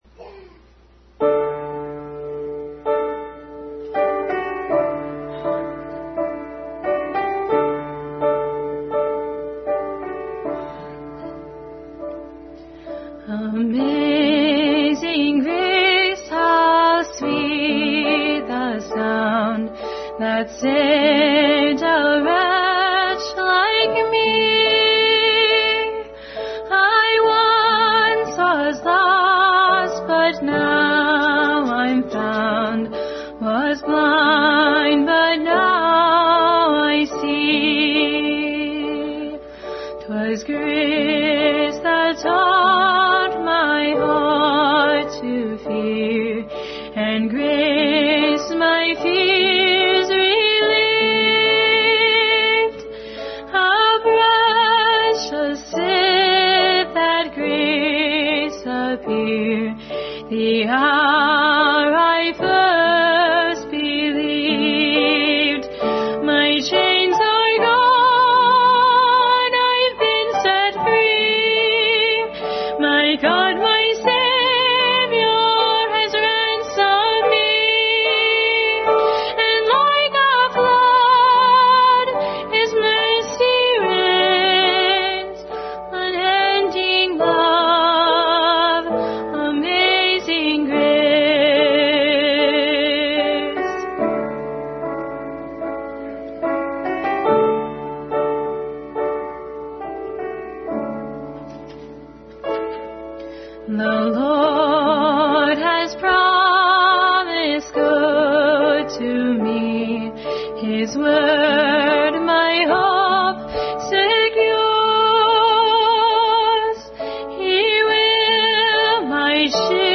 Special music
2 Corinthians 9:15 Service Type: Family Bible Hour Special music